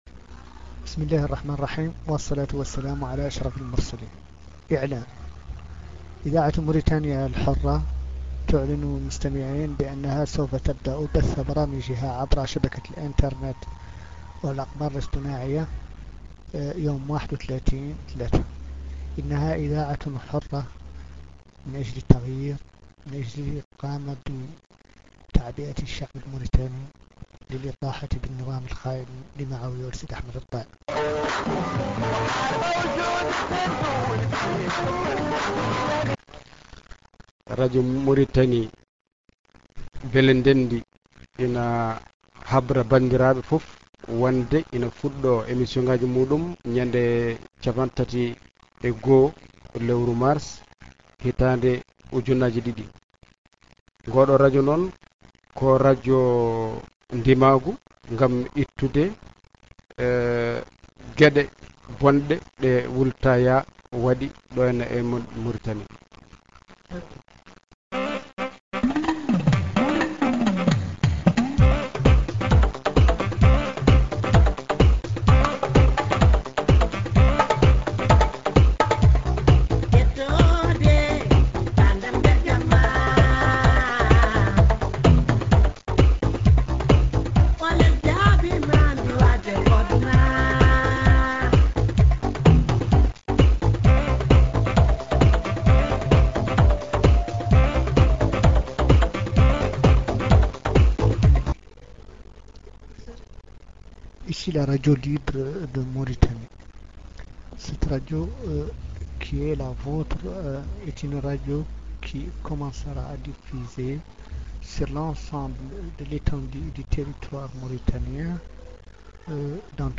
webストリーミングは、99年から01年にかけての録音です。